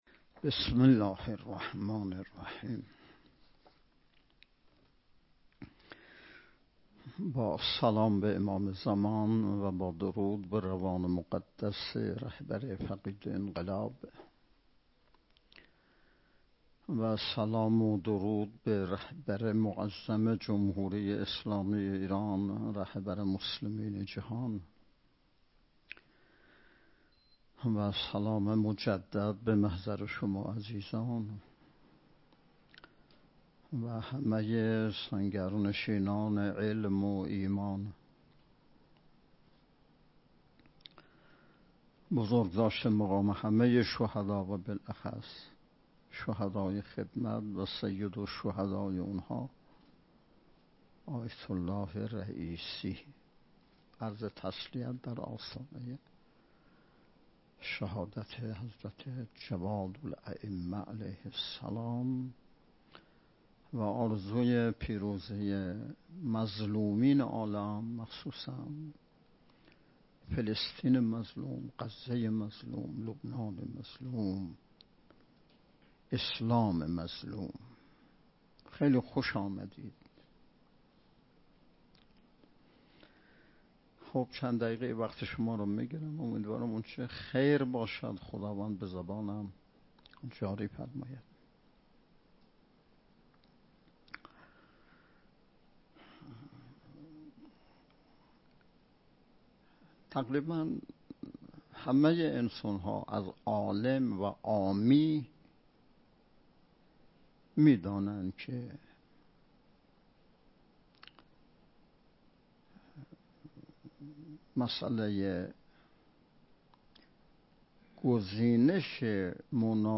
صوت / بیانات نماینده ولی فقیه در خراسان جنوبی در دیدار با مدیران و کارشناسان گزینش دانشگاه های منطقه ۹ کشور